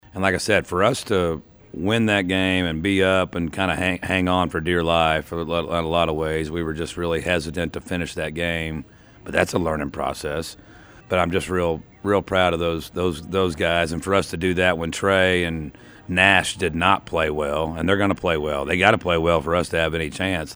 Our annual high school basketball preview show featured coaches from all across the area.